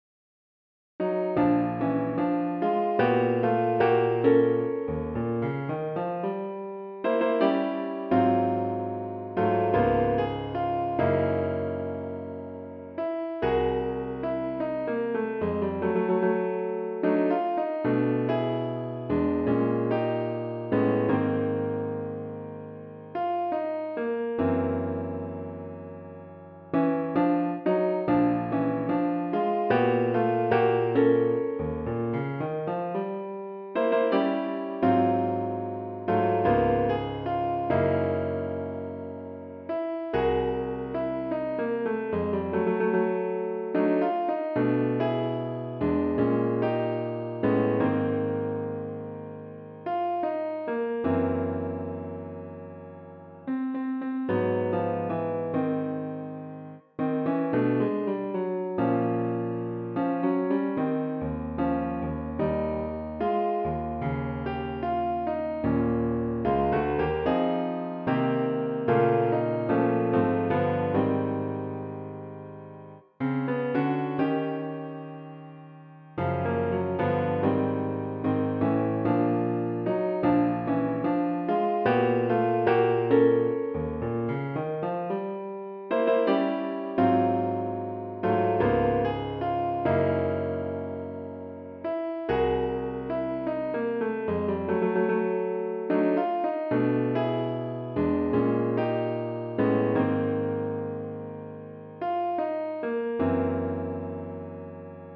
We have it in Ab major.